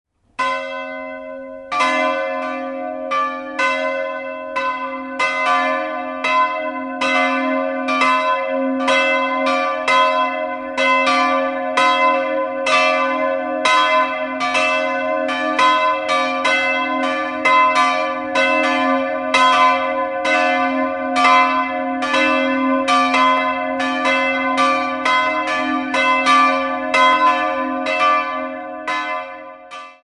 2-stimmiges Kleine-Terz-Geläute: c''-es''